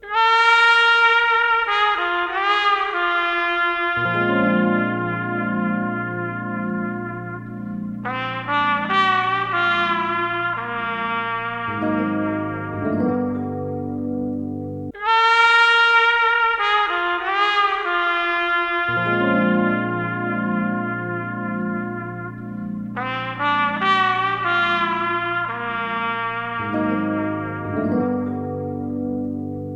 • Качество: 187, Stereo
OST
Неспешный рингтон для тех, кто никуда не торопится :)